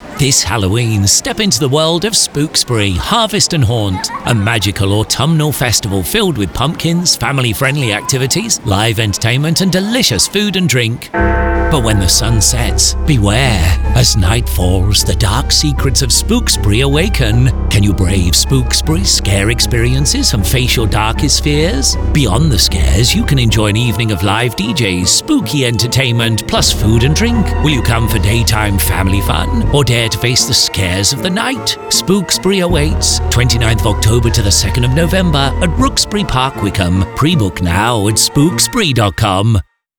Radio advertisement for my event.
Radio